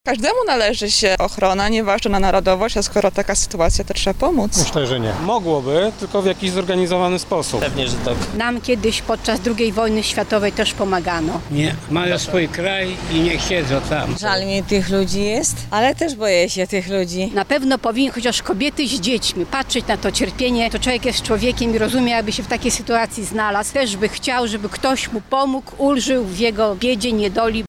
O to, czy województwo lubelskie powinno przyjąć uchodźców z Afganistanu, zapytaliśmy mieszkańców Lublina:
Mieszkańcy Lublina